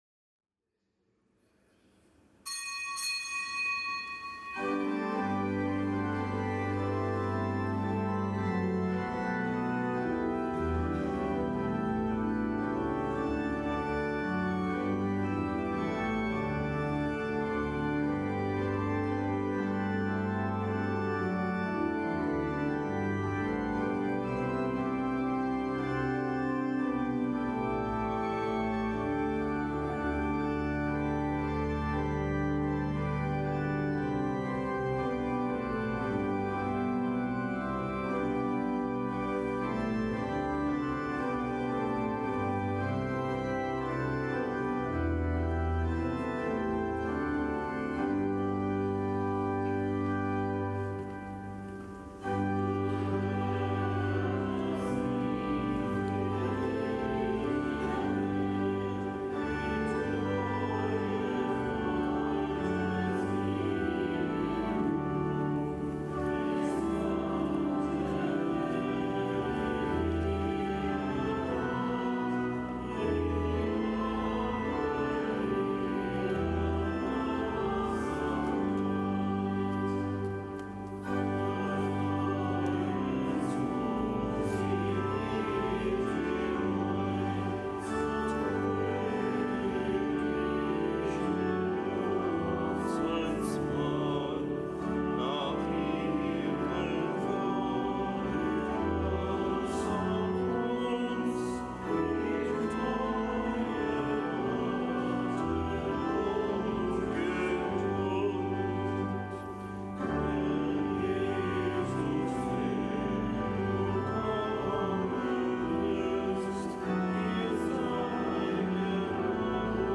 Kapitelsmesse am Gedenktag der Heiligen Edith Stein
Kapitelsmesse aus dem Kölner Dom am Gedenktag der Heiligen Edith Stein.